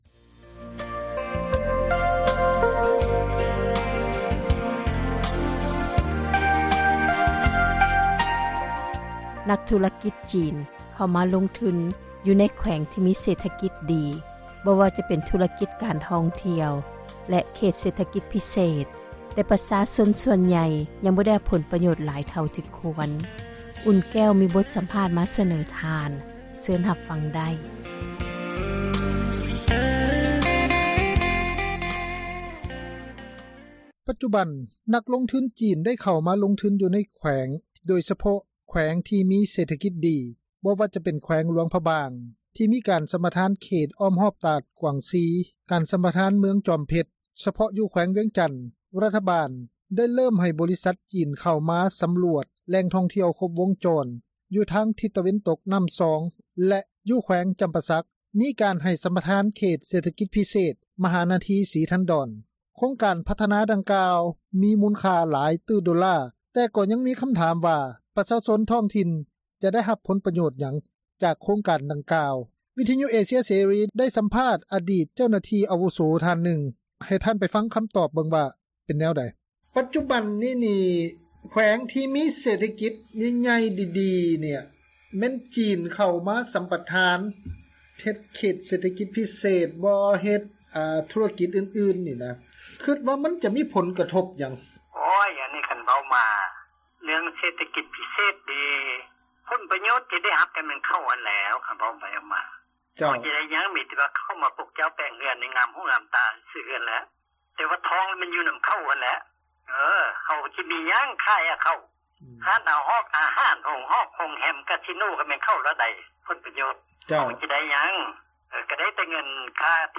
ໂຄງການພັທນາດັ່ງກ່າວ ມີມູນຄ່າຫລາຍຕື້ໂດລາ, ແຕ່ກໍຍັງມີຄຳຖາມຢູ່ວ່າ ປະຊາຊົນທ້ອງຖິ່ນຈະໄດ້ຮັບຜົນປະໂຫຍດຫຍັງ ຈາກໂຄງການ ດັ່ງກ່າວ, ວິທຍຸເອເຊັຍເສຣີ ໄດ້ສຳພາດ ອາດີດ ເຈົ້າໜ້າທີ່ອາວຸໂສ ທ່ານນຶ່ງ ກ່ຽວກັບເລື້ອງນີ້.